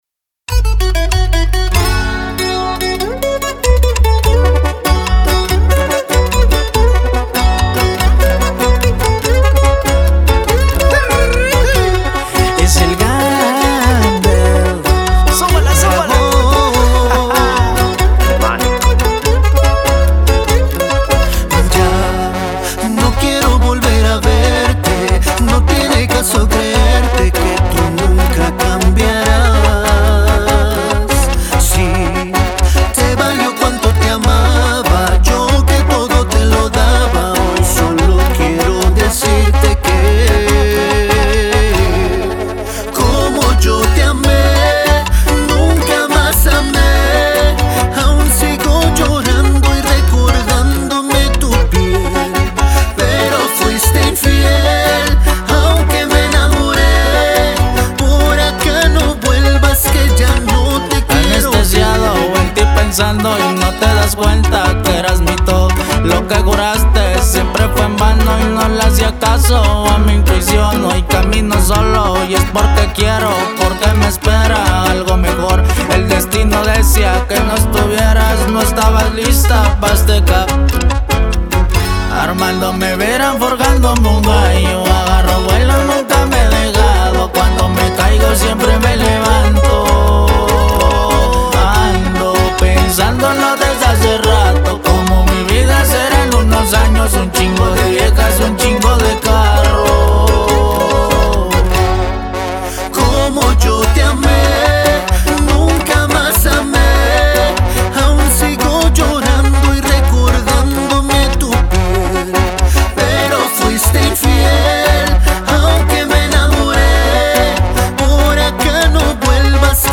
corrido tumbado